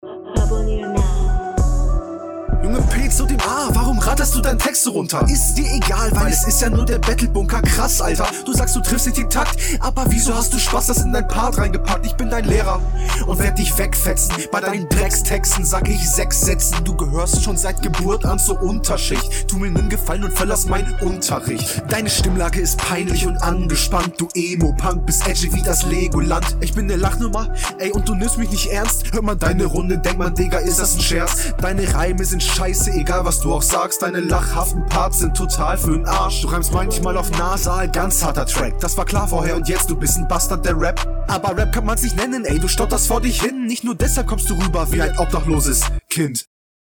deutlich angenehmer als dein gegner, flowtechnisch hast du mehr auf dem kasten, scheinst also auch …